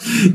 Play, download and share 『吸氣』 original sound button!!!!
xi-qi.mp3